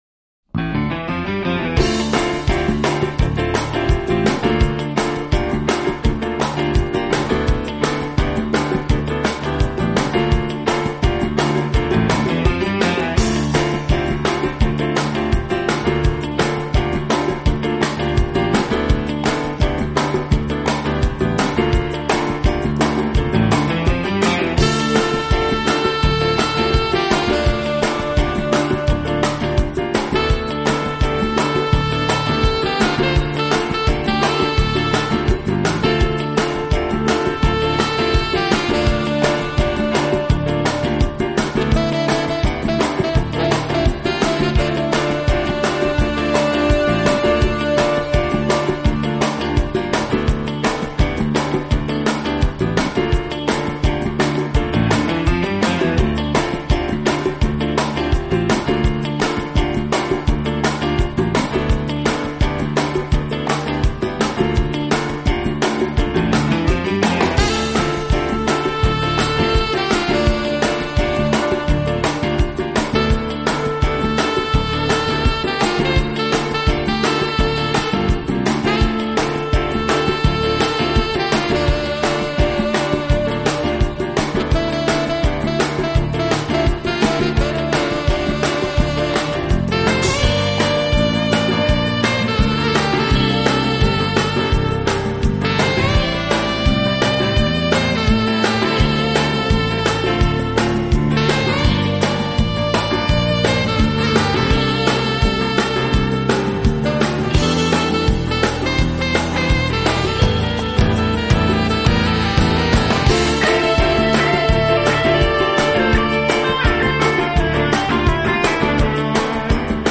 Smooth Jazz 不同凡响的见解，让他的音乐作品如同森林般深邃而充满活力。
其标志性的特点是在自然音阶中揉入打击乐和电子音乐，再加上吉它、电贝斯、
长笛和萨克斯管演奏片断。
柔柔的音乐，优美的乐章如同森林般深邃而充满活力，音场广阔无限。
幻而不确定性，这也如同绘画中的印象派。